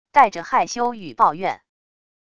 带着害羞与抱怨wav音频